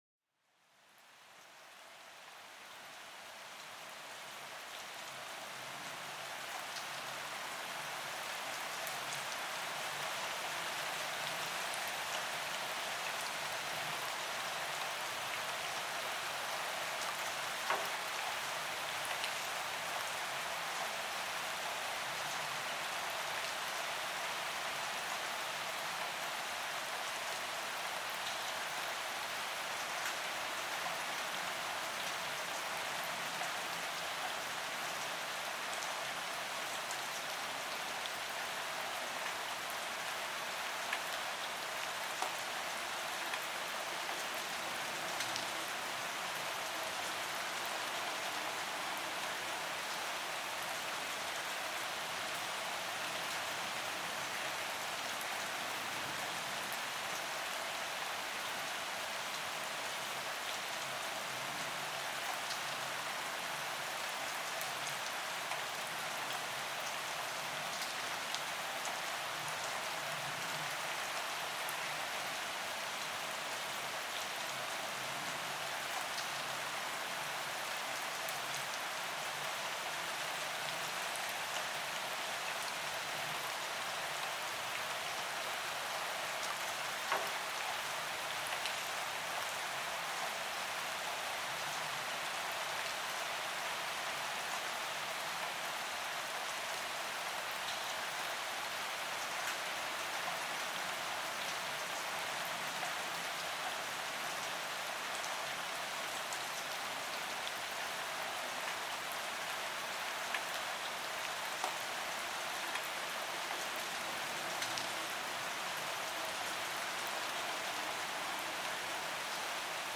Pluie Douce : Une Mélodie Apaisante pour l'Esprit
Plongez dans un univers de sérénité avec le doux murmure de la pluie tombant doucement sur des feuilles et des sols humides. Chaque goutte crée une harmonie parfaite qui apaise l'esprit et soulage les tensions accumulées.